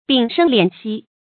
屏声敛息 píng shēng liǎn xī
屏声敛息发音
成语注音 ㄅㄧㄥˇ ㄕㄥ ㄌㄧㄢˇ ㄒㄧ